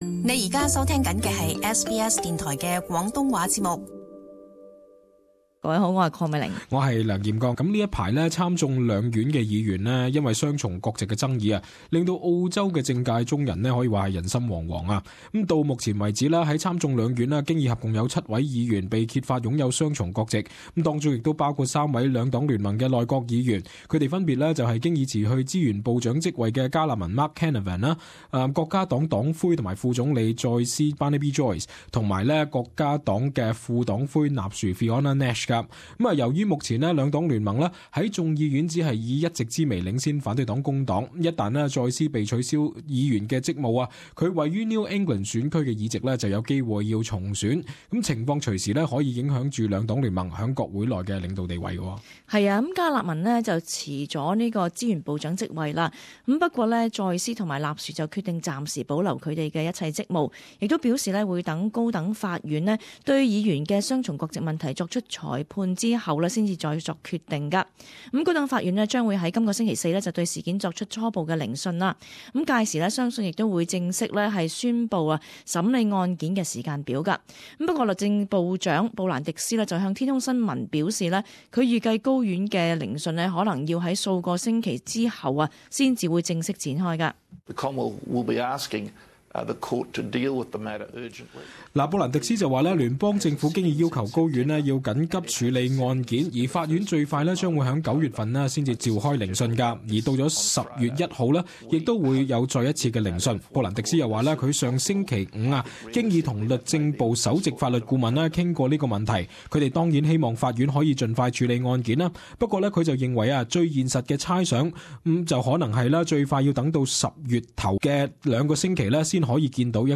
【时事报导】布兰迪斯：法律最快十月处理议员双重国籍风波